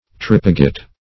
trepeget - definition of trepeget - synonyms, pronunciation, spelling from Free Dictionary Search Result for " trepeget" : The Collaborative International Dictionary of English v.0.48: Trepeget \Trep"e*get\, n. (Mil.)